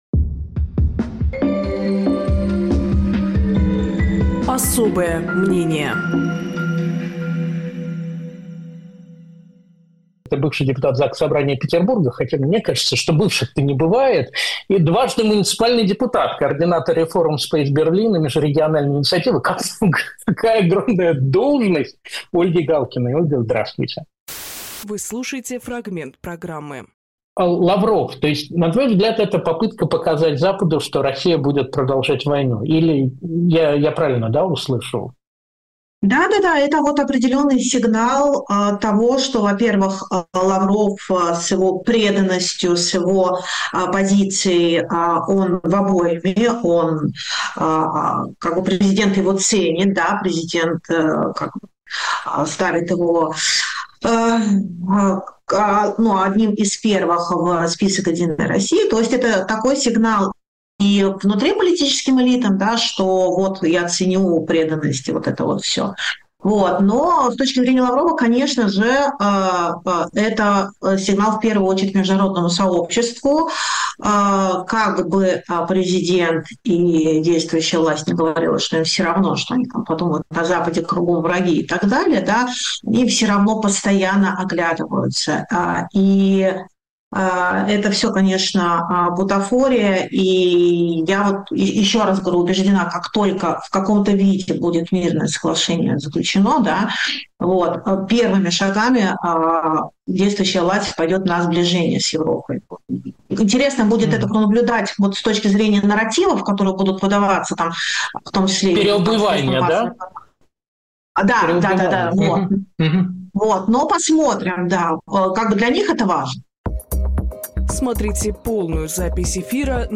Фрагмент эфира от 20.01.26